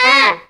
OCTAVE FALL.wav